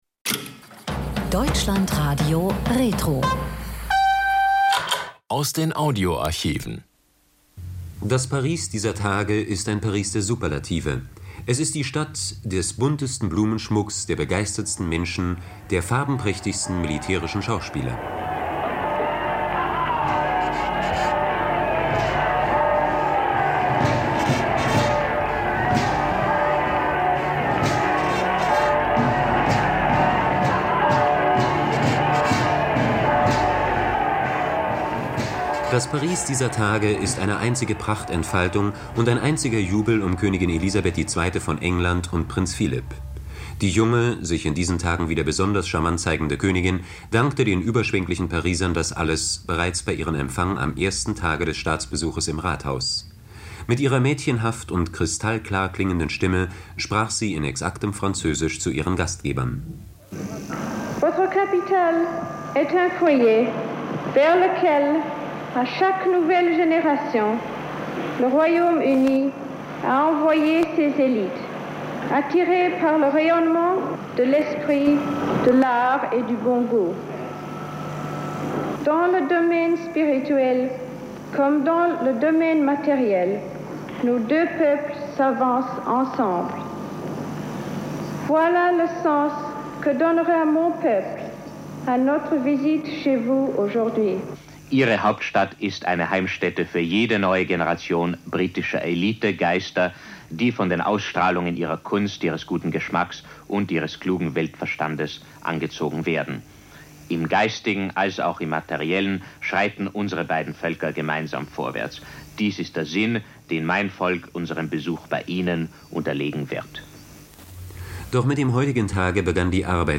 RIAS-Korrespondent Georg Troller berichtet vom Staatsbesuch von Königin Elizabeth II. in Paris, wo sie gefeiert wird und auf Französisch zu Gastgebern spricht.